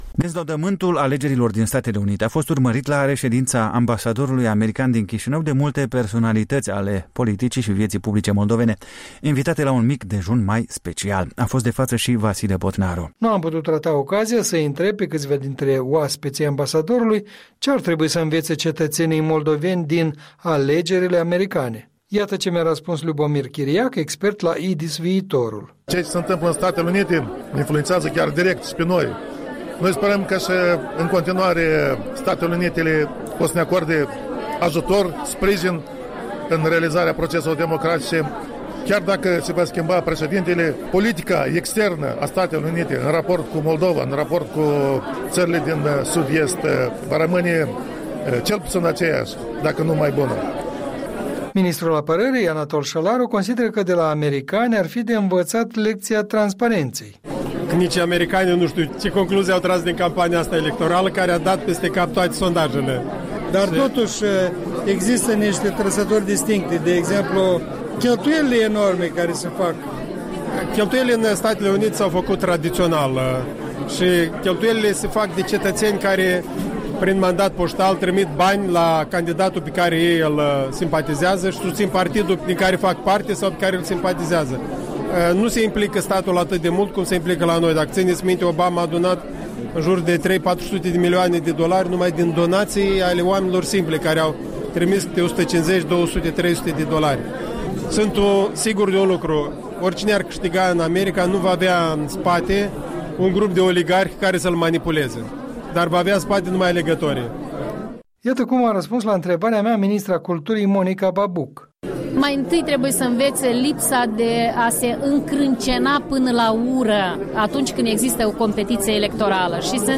Opinii culese la dineul oficial de la ambasada SUA din Chișinău organizat cu ocazia scrutinului prezidențial american